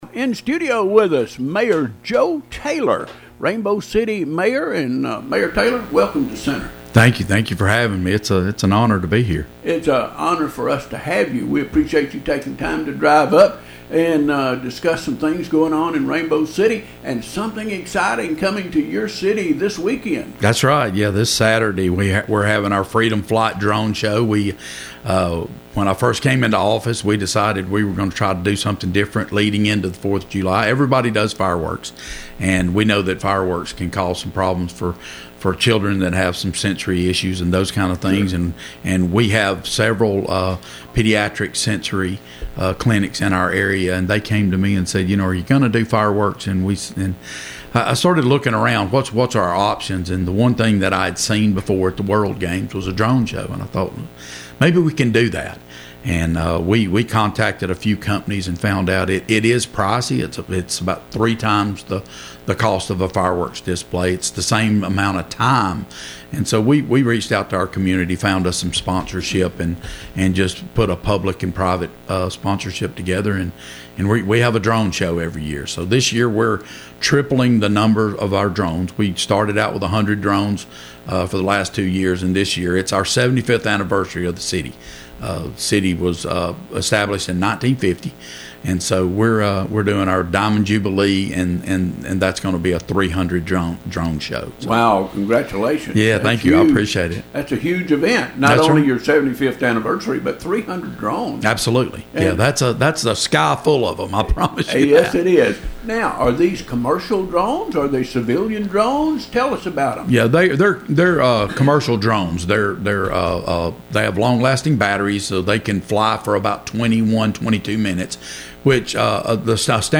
Rainbow City Mayor Joe Taylor Highlights Major Events and Growth Initiatives in Interview with WEIS Radio
Rainbow City is preparing for a summer filled with community events, innovative initiatives, and continued growth, according to Mayor Joe Taylor, who joined WEIS Radio on Wednesday morning to share what’s ahead for the city.